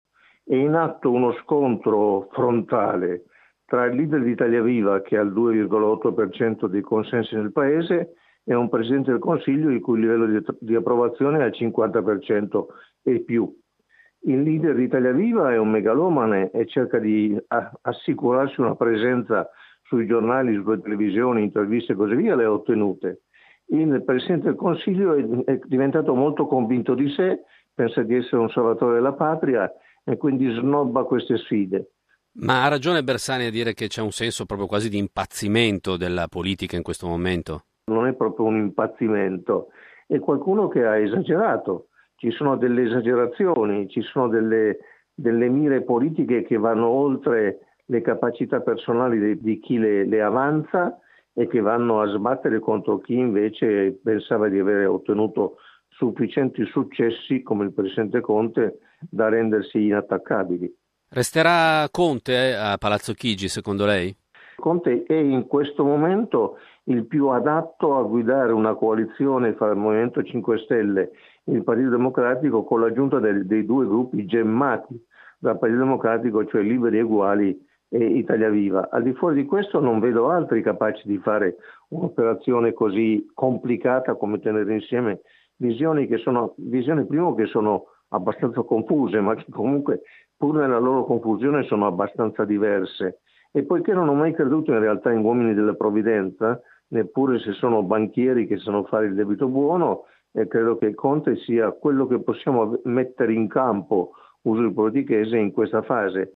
Abbiamo raccolto tre opinioni.
Gianfranco Pasquino, politologo dell’università di Bologna: